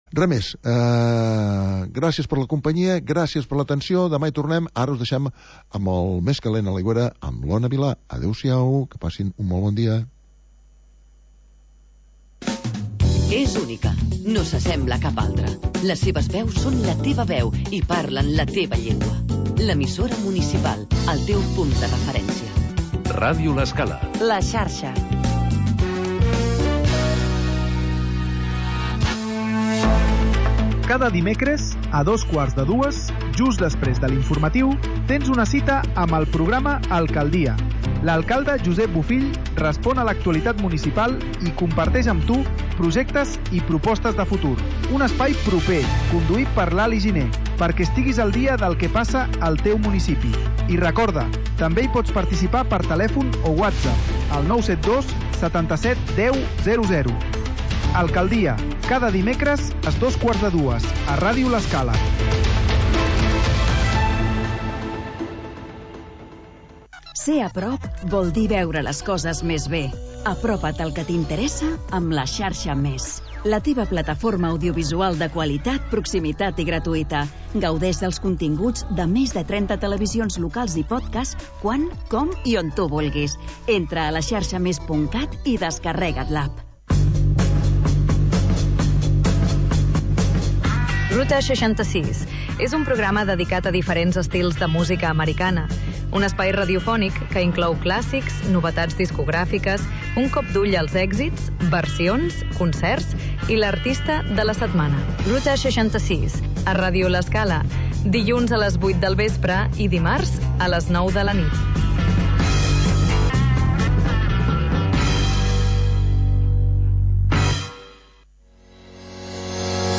Magazin d'entretiment per acompanyar el migdiaompanyar